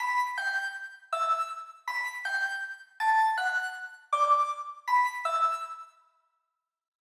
BackYard Melody 4 (Flute).wav